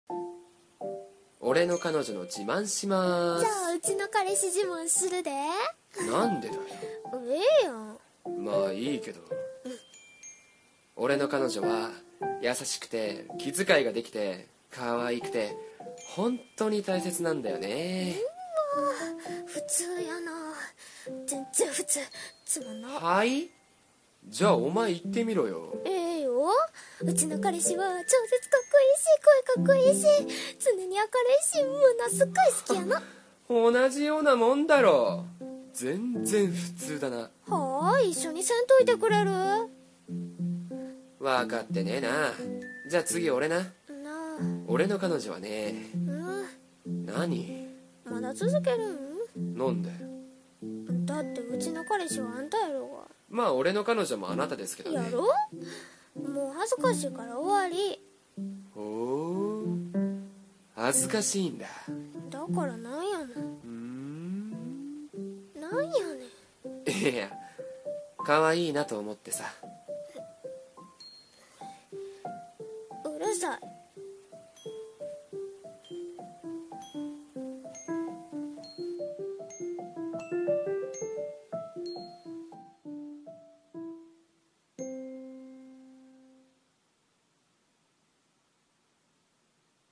声劇(掛け合い声面接)〜自慢遊び〜